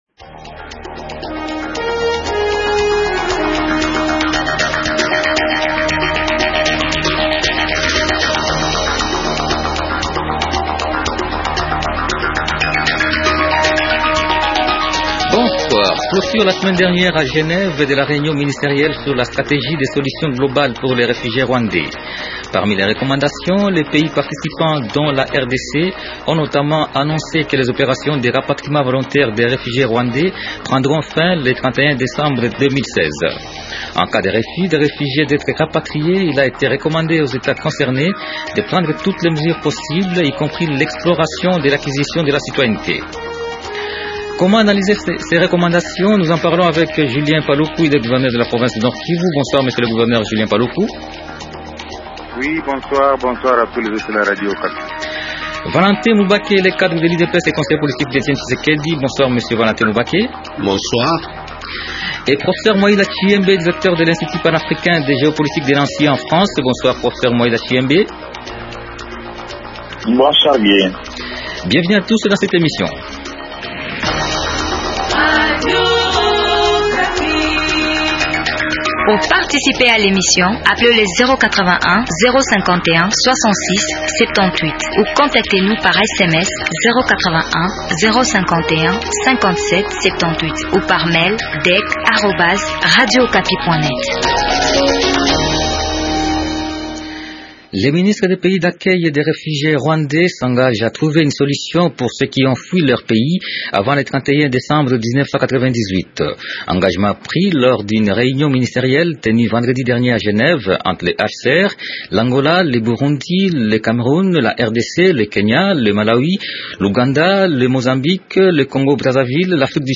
Les débatteurs de ce soir sont : -Julien Paluku, gouverneur de la province du Nord-Kivu.